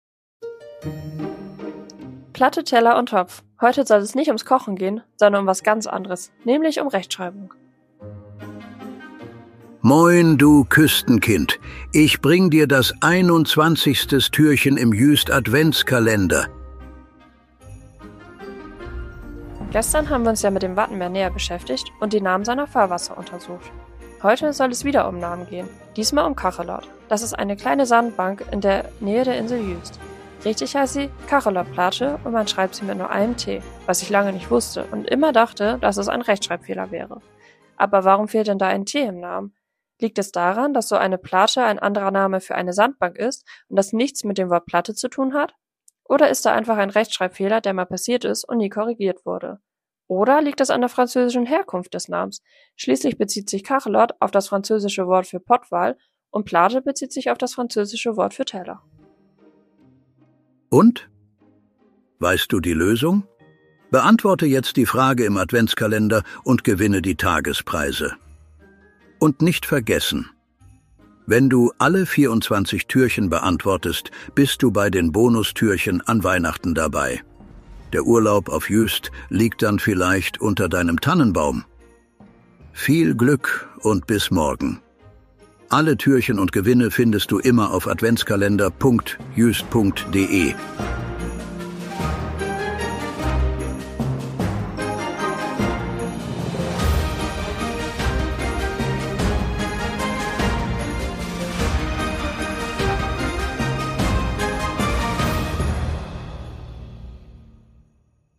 füttern wollen. Eingesprochen wird der Adventskalender von vier
guten Geistern der Insel Juist, die sich am Mikro abwechseln und